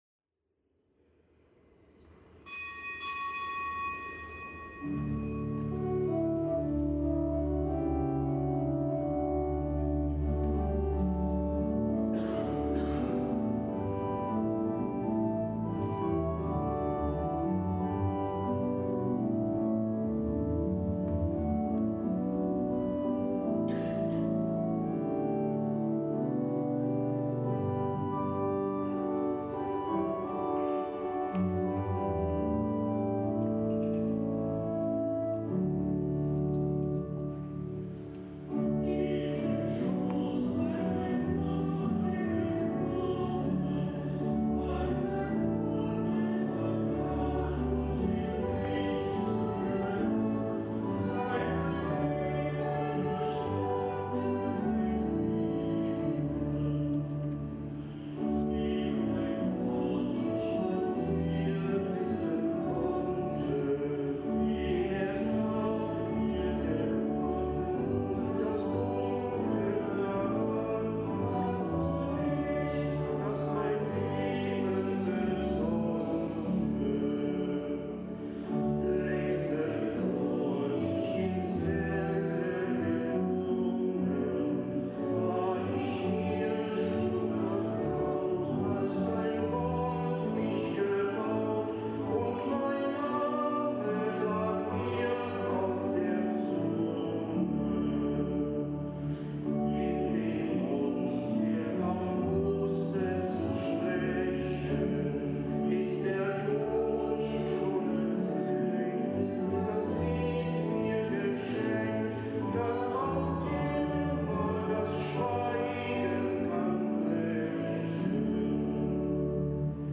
Kapitelsmesse aus dem Kölner Dom am Gedenktag der Heiligen Theresia vom Kinde Jesus (von Lisieux), Ordensfrau und Kirchenlehrerin.